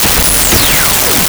Laser
laser.wav